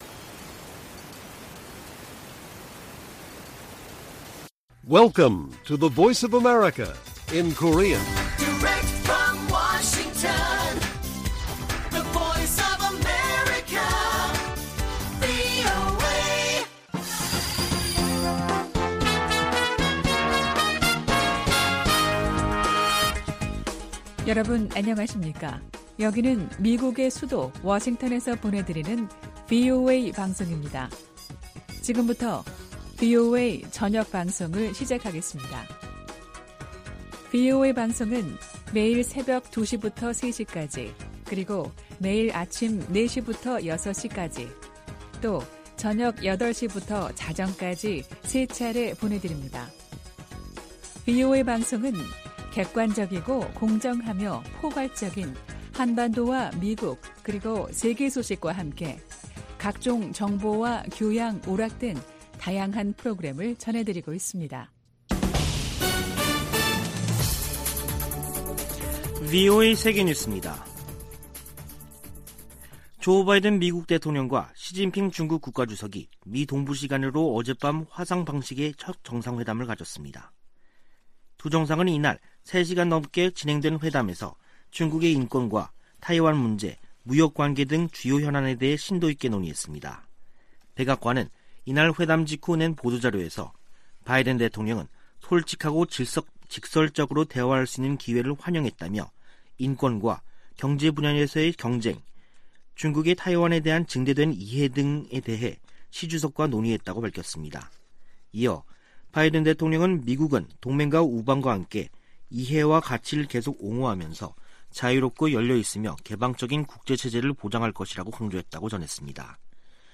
VOA 한국어 간판 뉴스 프로그램 '뉴스 투데이', 2021년 11월 16일 1부 방송입니다. 미 국방부 부차관보는 실질적 ‘대북 조치’ 가 준비 돼 있다면서도, 위협 행위는 간과하지 않겠다고 강조했습니다. 디 셔먼 미 국무부 부장관이 이번 주 한국과 일본의 외교차관과 워싱턴에서 연쇄 회동을 가집니다.